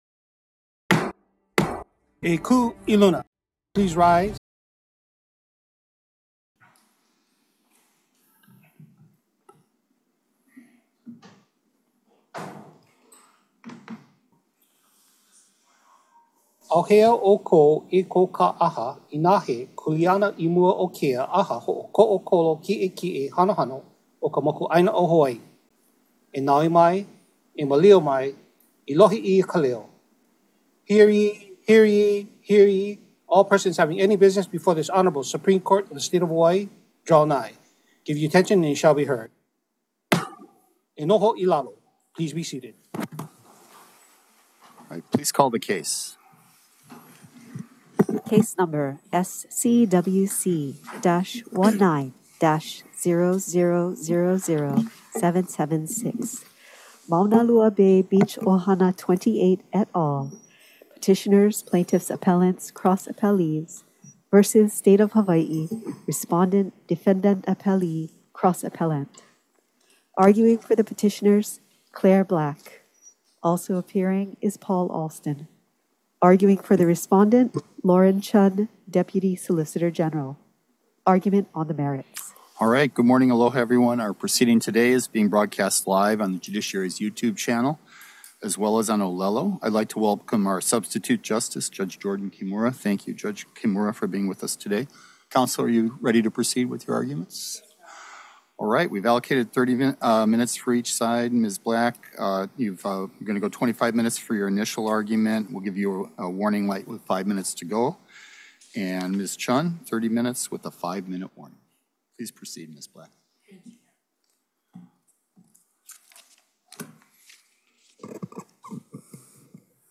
The above-captioned case was set for oral argument on the merits at: Supreme Court Courtroom Ali ‘ iōlani Hale, 2 nd Floor 417 South King Street Honolulu, HI 96813